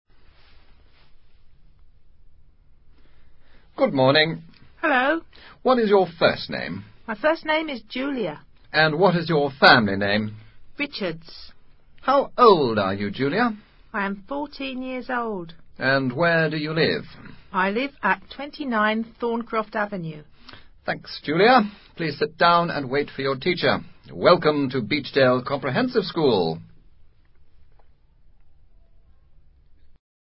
Una joven se presenta y habla acerca de su edad, residencia y familia.